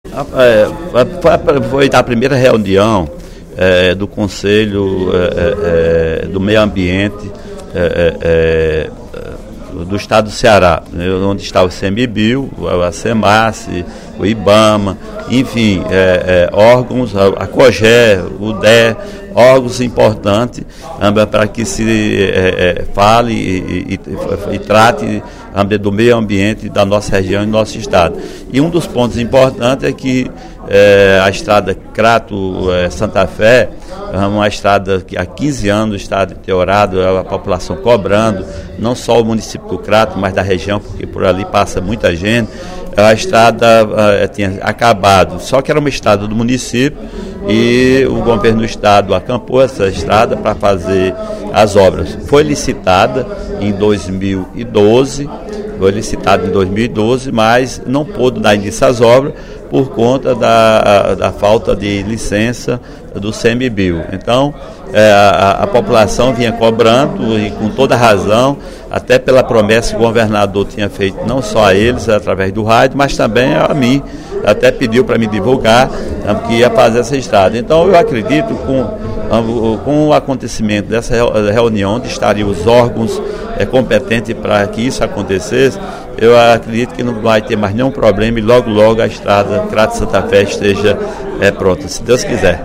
O deputado Sineval Roque (PSB) registrou, nesta quinta-feira (25/04), durante o primeiro expediente, a primeira reunião do Conselho Consultivo da Área de Proteção Ambiental (APA ) da Chapada do Araripe, de 2013, que ocorreu ontem (26) no município do Crato.